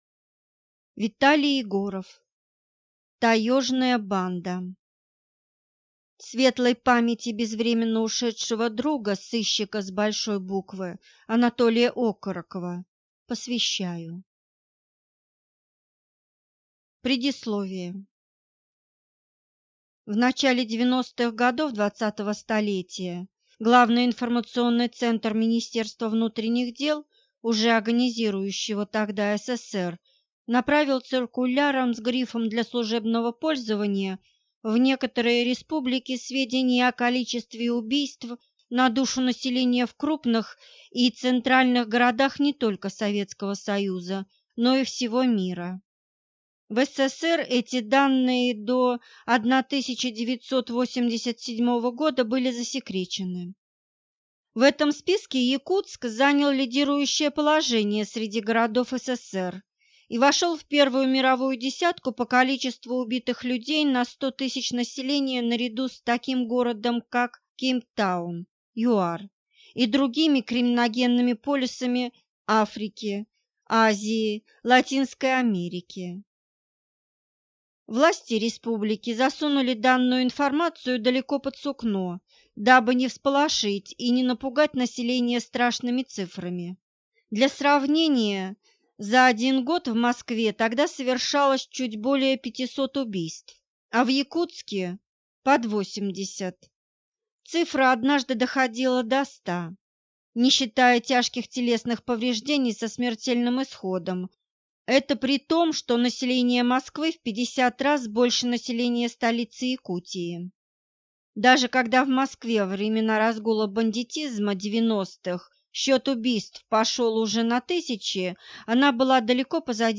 Аудиокнига Таежная банда | Библиотека аудиокниг